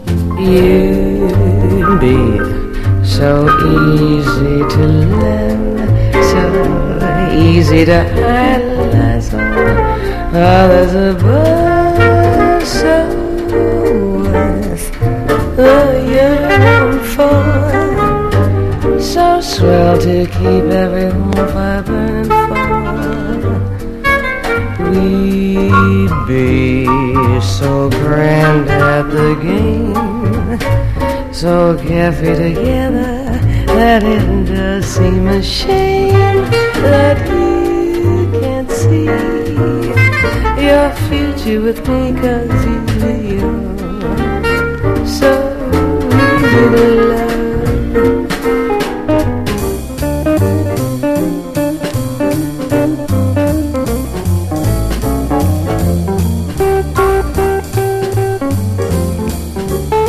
JAZZ / JAZZ VOCAL